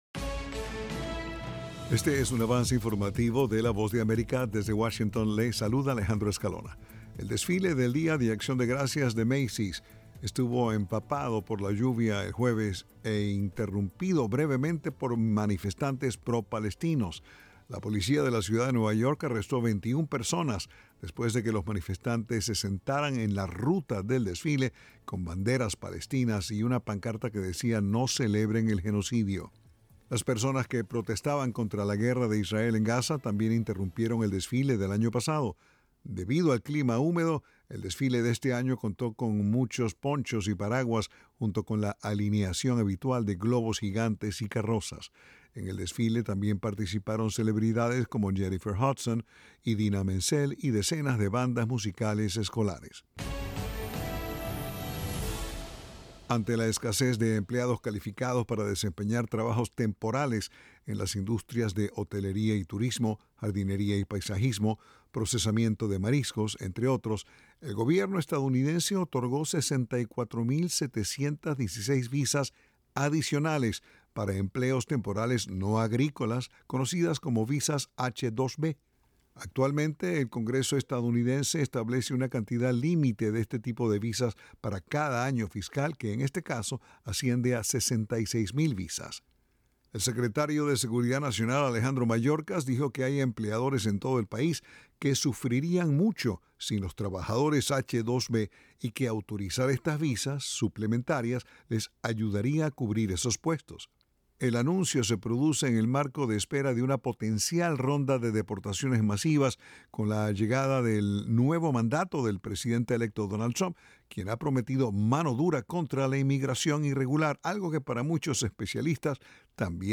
El siguiente es un avance informativo presentado por la Voz de América.